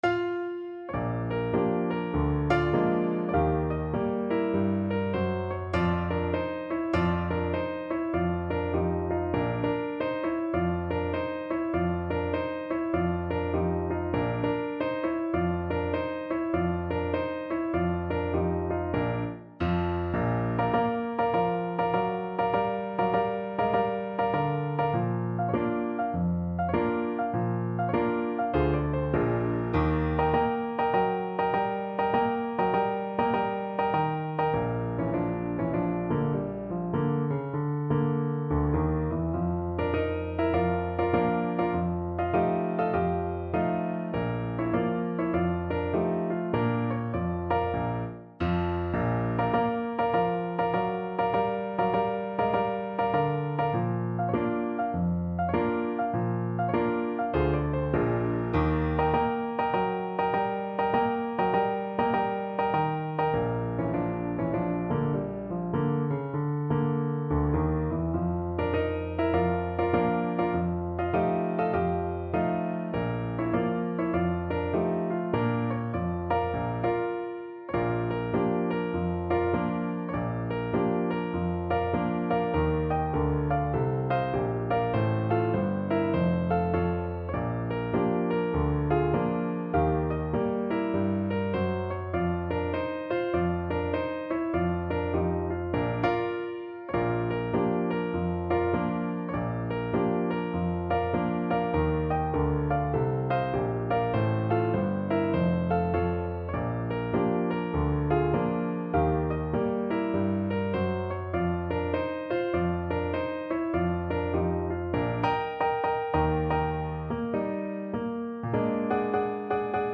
No parts available for this pieces as it is for solo piano.
4/4 (View more 4/4 Music)
Piano  (View more Intermediate Piano Music)
Jazz (View more Jazz Piano Music)